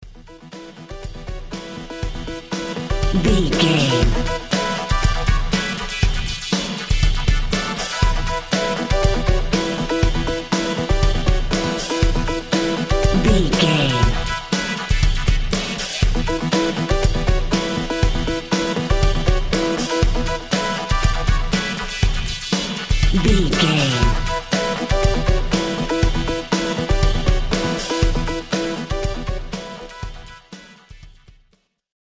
Breakbeat Electro Music Cue.
Aeolian/Minor
groovy
uplifting
futuristic
driving
energetic
drum machine
synthesiser
synth lead
synth bass